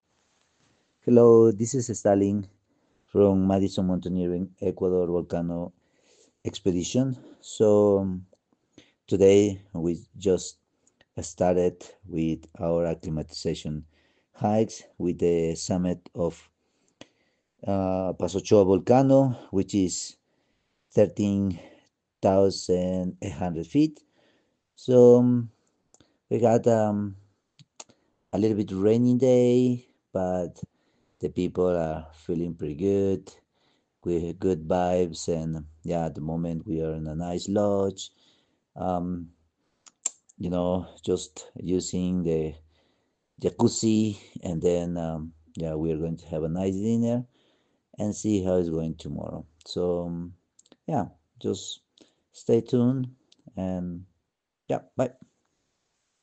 • Enable the skill and add to your flash briefing to hear our daily audio expedition updates on select expeditions.